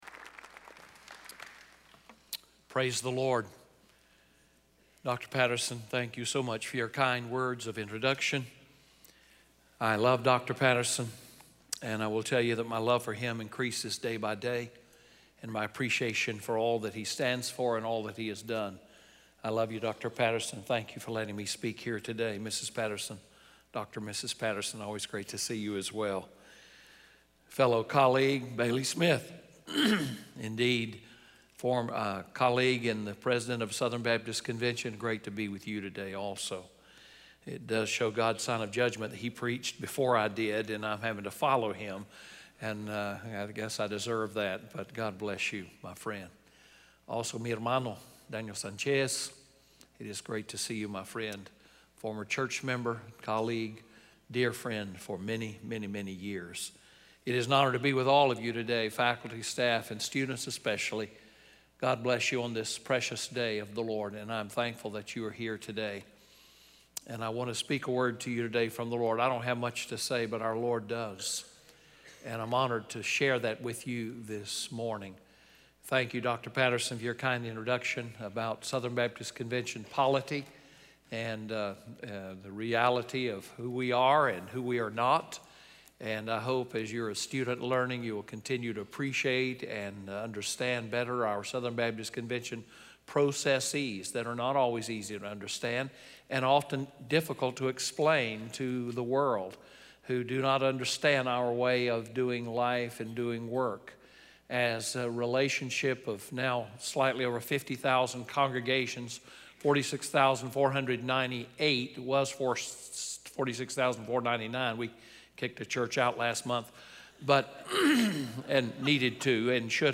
Philippians 1:21-26 in SWBTS Chapel